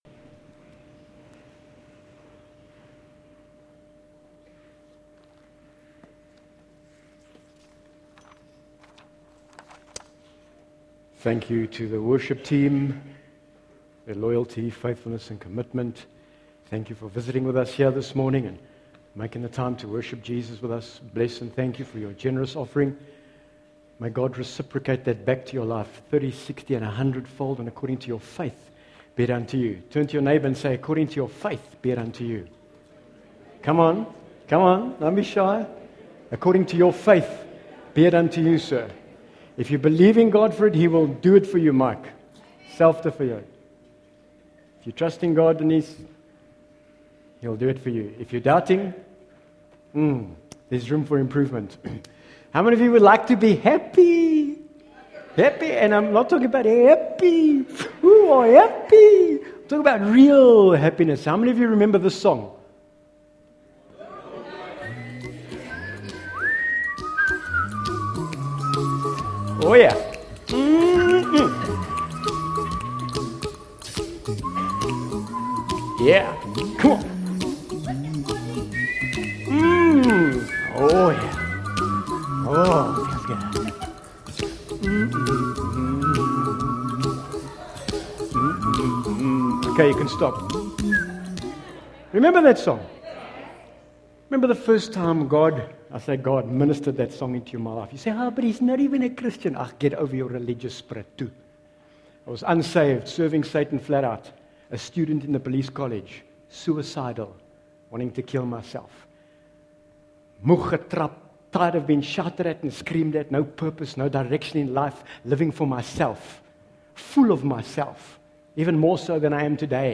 Bible Text: MATTHEW 5:1-11 | Preacher